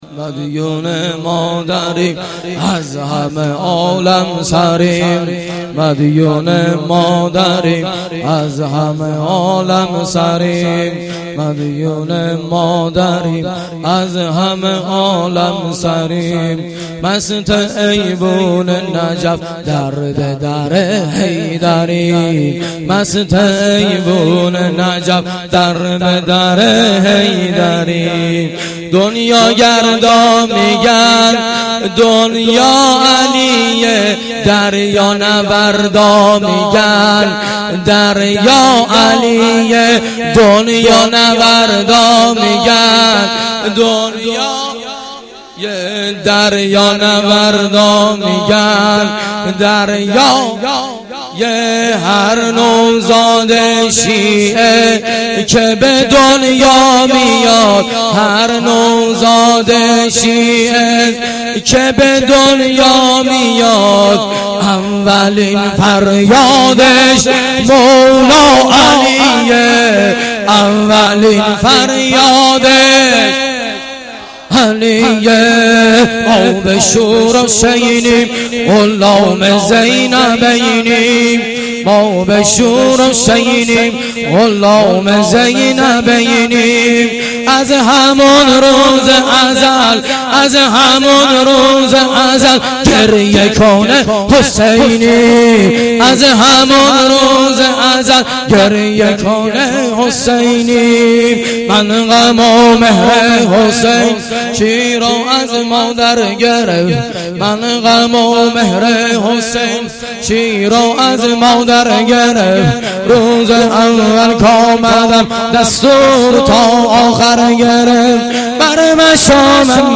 گزارش صوتی جلسه هفتگی2دیماه